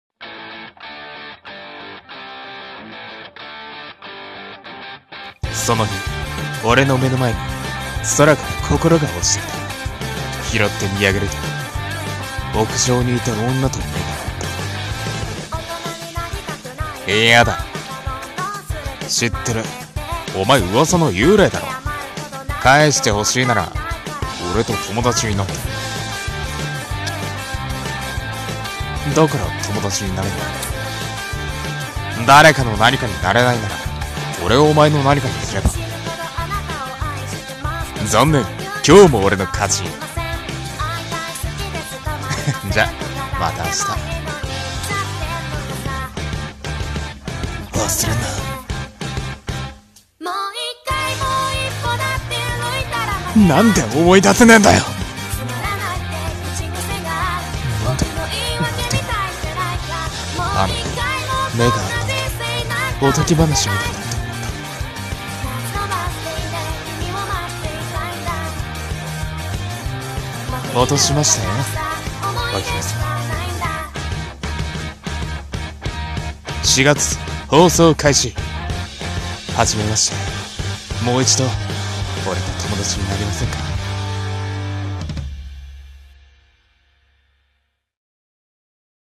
【アニメCM風声劇】